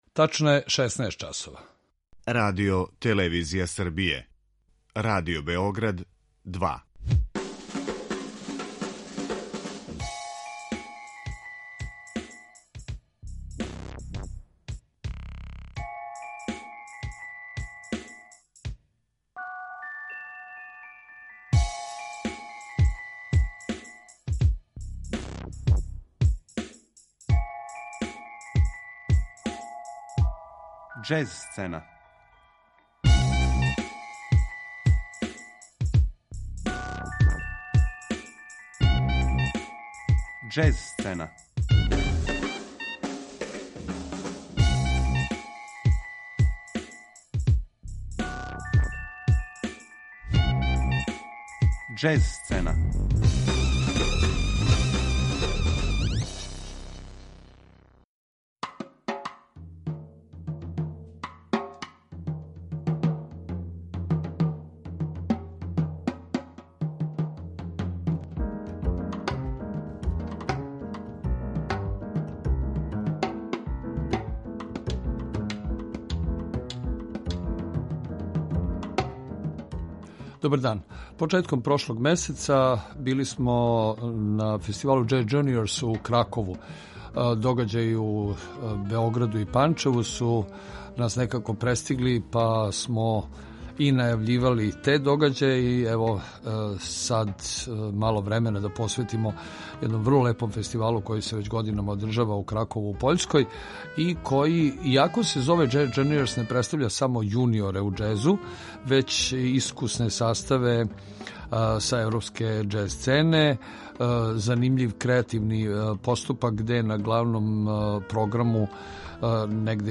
У емисији слушамо снимке седам младих пољских састава који су наступили на овој манифестацији.